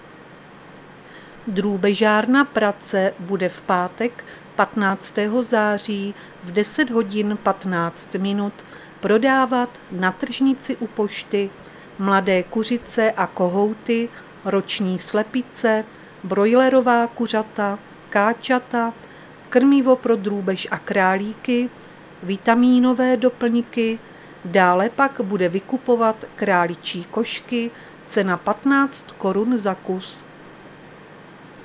Záznam hlášení místního rozhlasu 14.9.2023